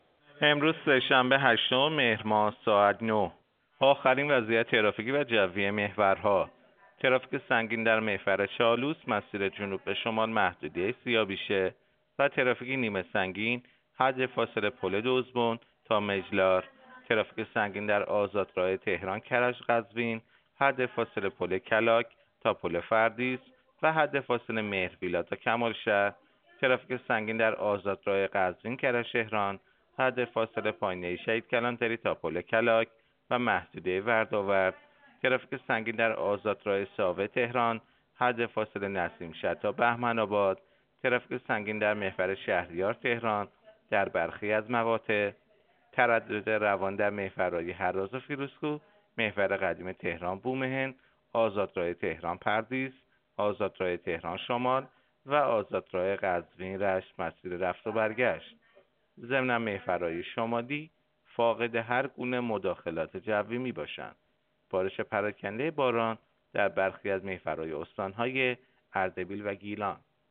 گزارش رادیو اینترنتی از آخرین وضعیت ترافیکی جاده‌ها ساعت ۹ هشتم مهر؛